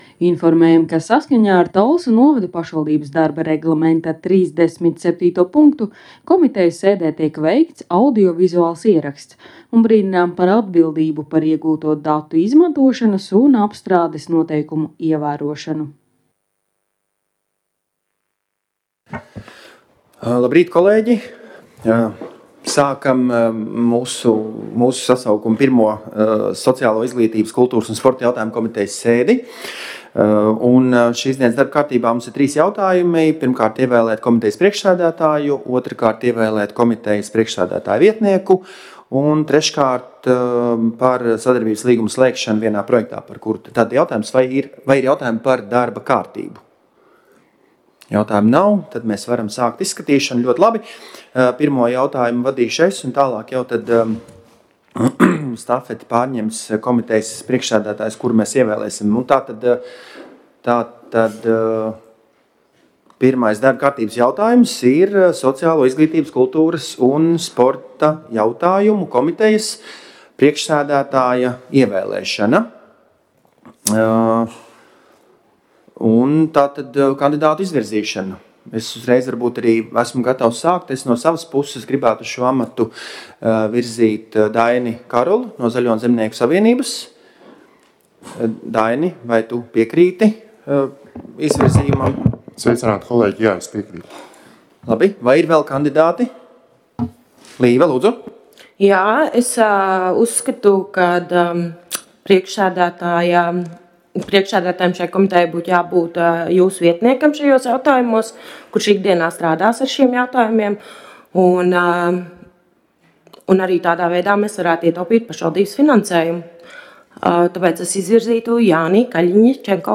Sociālo, izglītības, kultūras un sporta jautājumu komitejas sēde Nr. 1